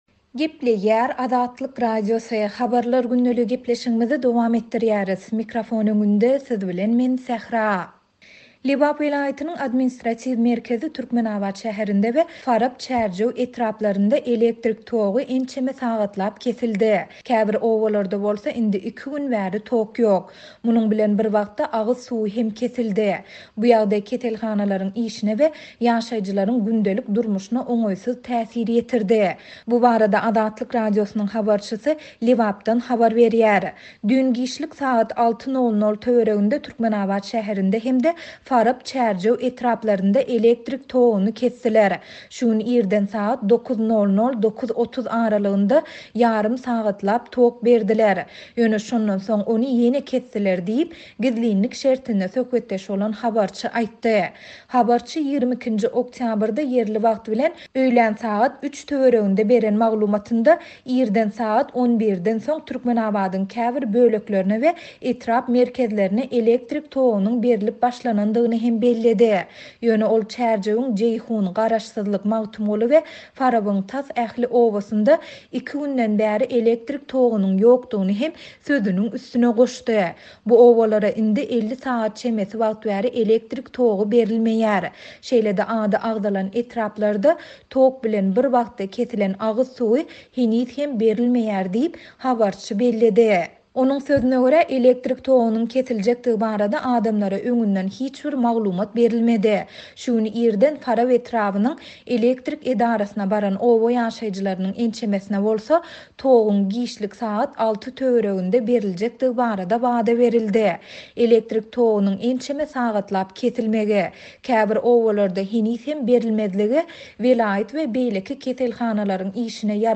Bu ýagdaý keselhanalaryň işine we ýaşaýjylaryň gündelik durmuşyna oňaýsyz täsir ýetirdi. Bu barada Azatlyk Radiosynyň habarçysy Lebapdan habar berýär.